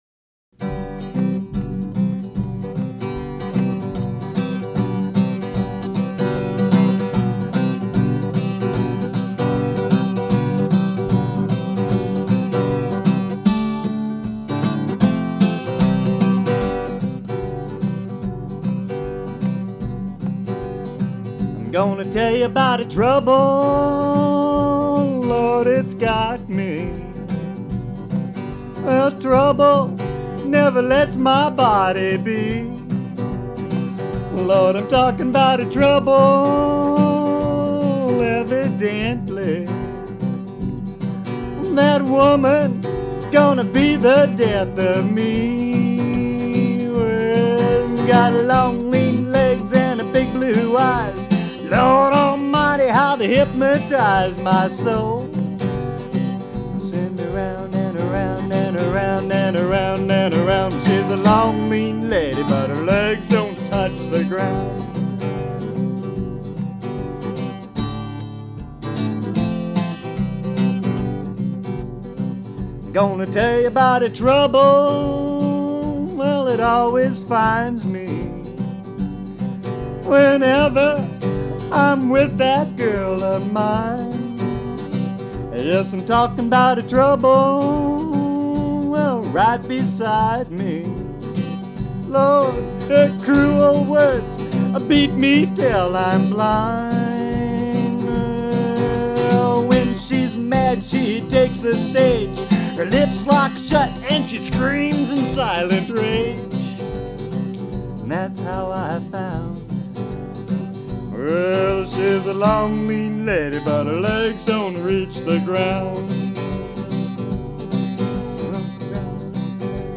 "Trouble unplugged"